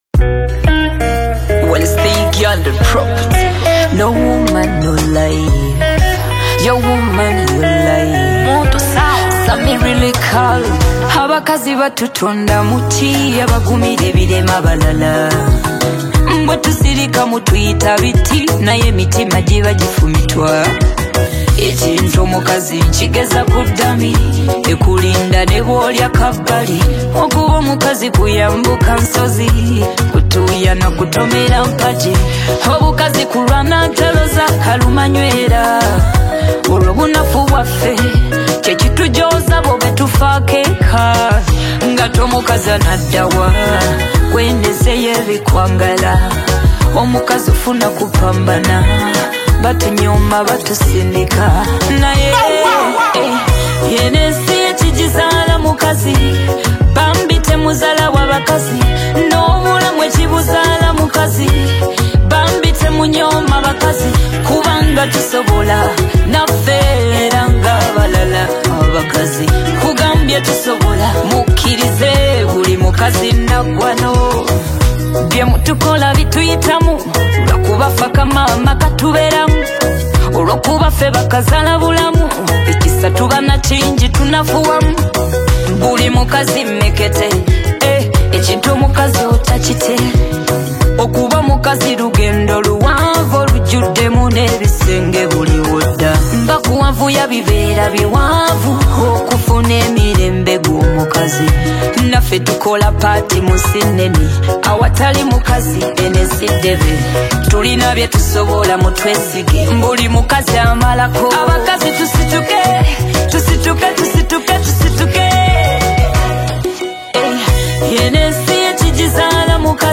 powerful and emotional song